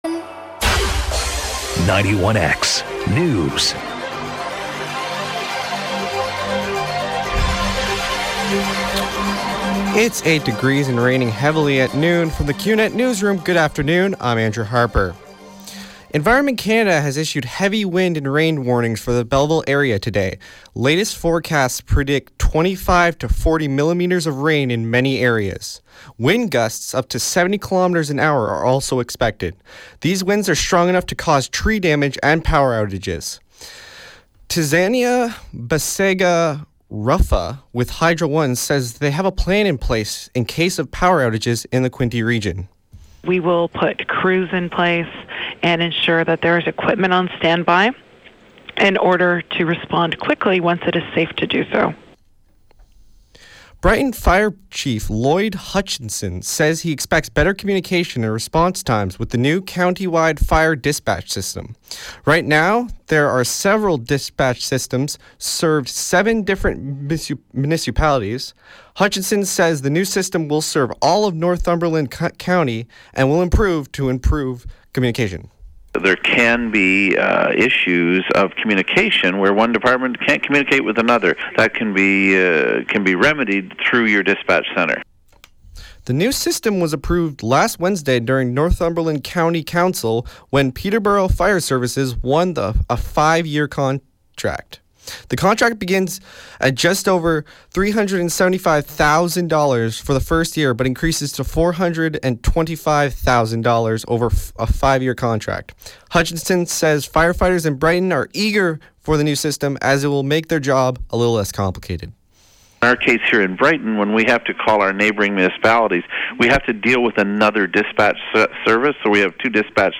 91X newscast – Wednesday, Oct. 28, 2015 – 12 noon